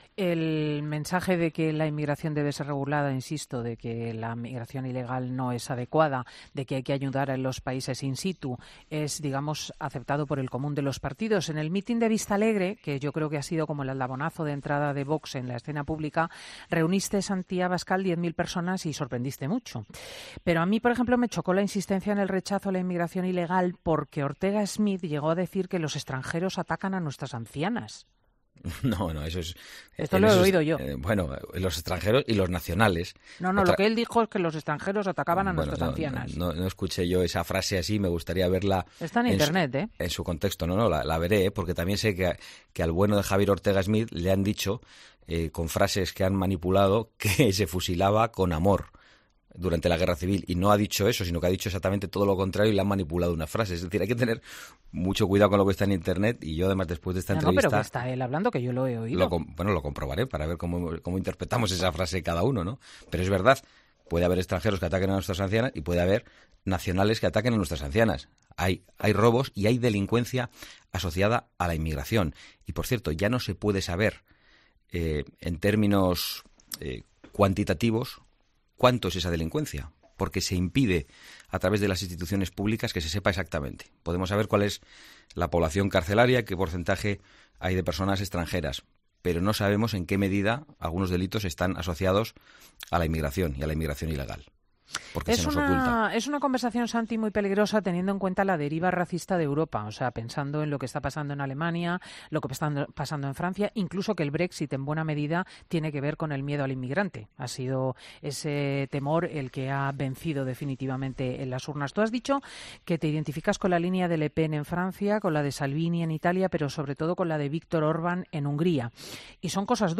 El presidente de VOX, Santiago Abascal,  y la directora de "Fin de semana", Cristina López Schlichting, han mantenido este domingo en COPE una tensa charla.